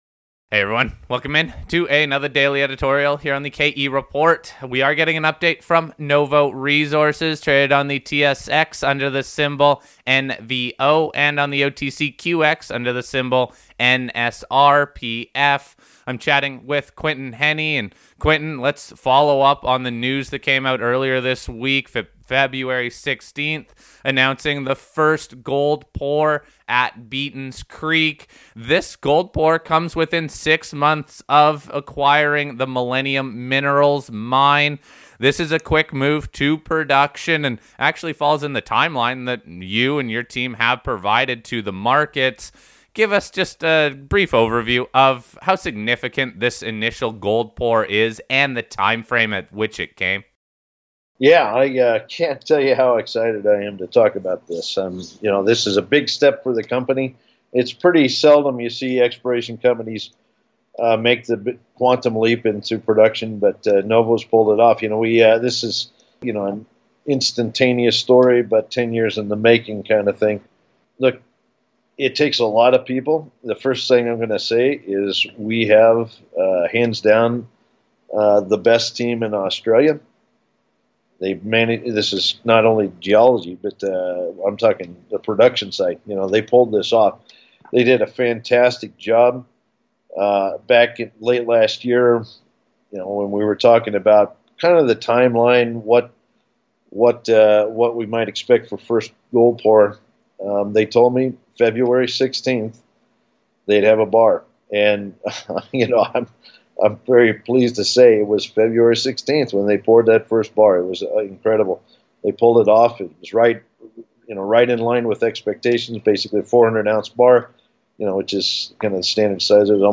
I wanted to keep this interview focused on the production aspect of the Company.